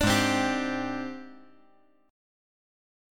AbM#11 Chord
Listen to AbM#11 strummed